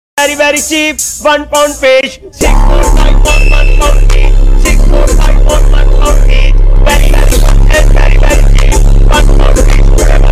Ear rape warning??? VERY VERY sound effects free download